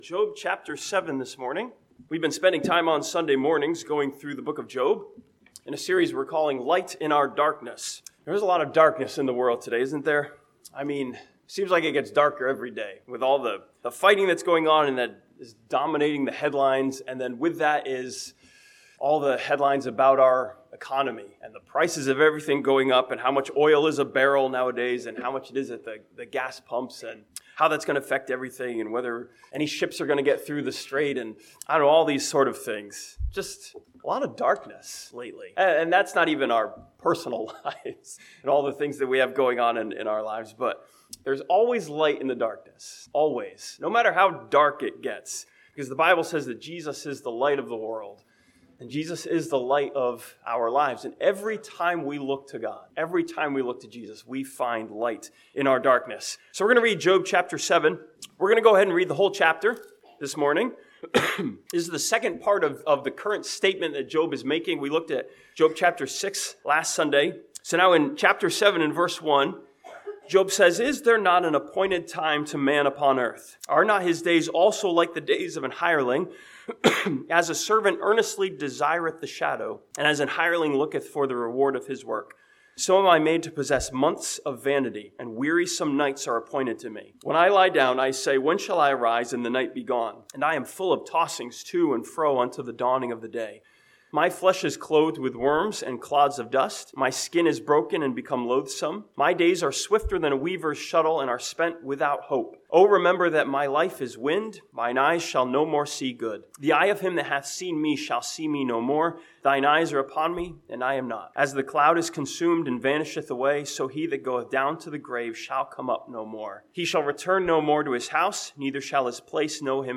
This sermon from Job chapter 7 challenges the believer to see the light of the reality of who we are in God's eyes.